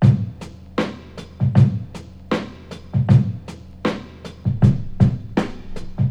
• 79 Bpm Drum Loop C# Key.wav
Free breakbeat sample - kick tuned to the C# note. Loudest frequency: 323Hz
79-bpm-drum-loop-c-sharp-key-oTI.wav